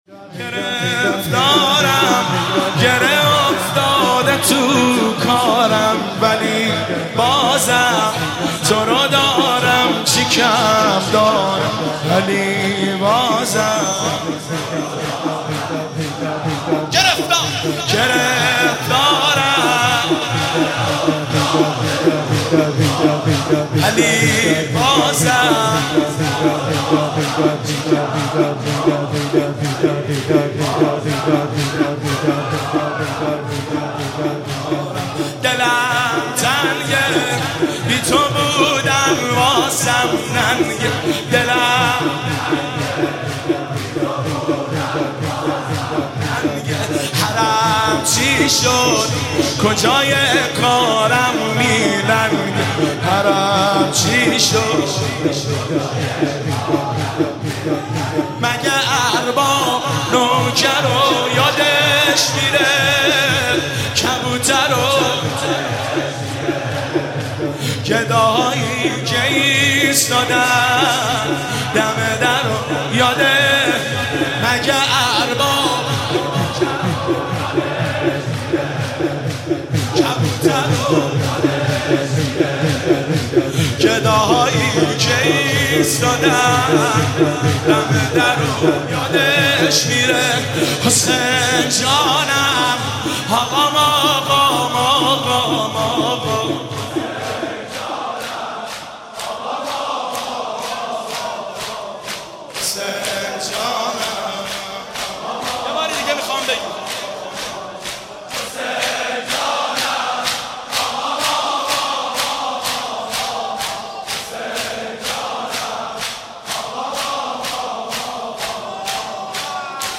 شب هفتم رمضان95
زمینه، روضه، مناجات